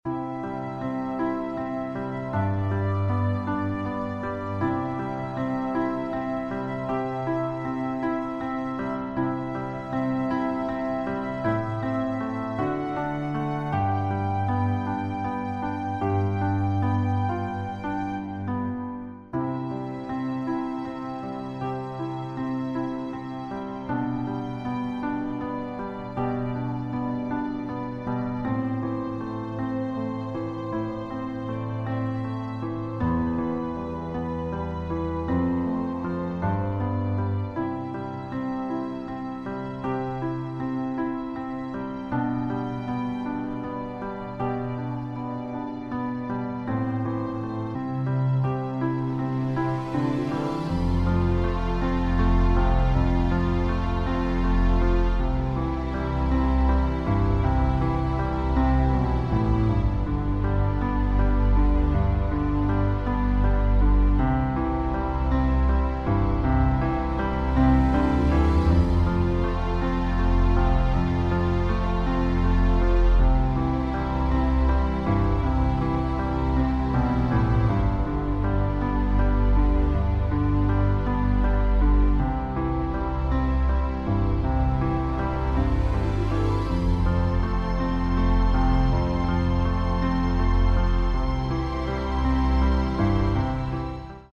• Tonart: A Dur, C Dur, E Dur
• Art: Klavier Streicher Version
• Das Instrumental beinhaltet NICHT die Leadstimme
Klavier / Streicher